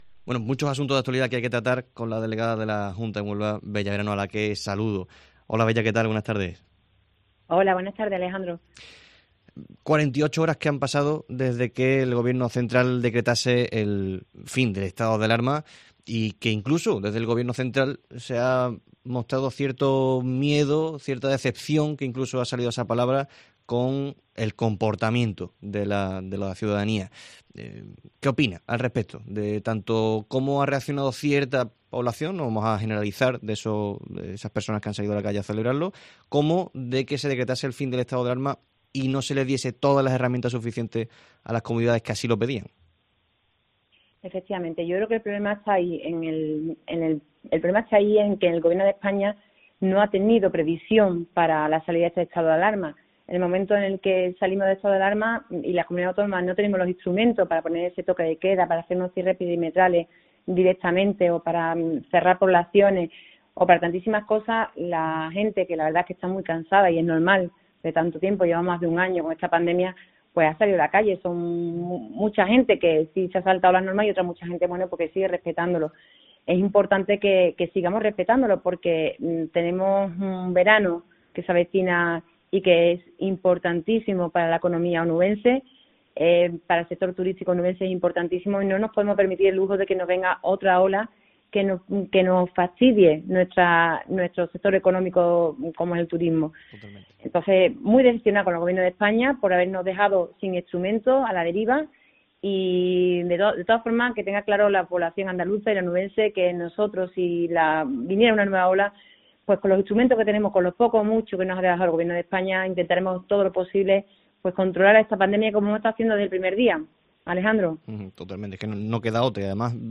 La delegada del gobierno andaluz en Huelva, Bella Verano, ha concedido una entrevista a COPE Huelva donde ha analizado diversos asuntos de...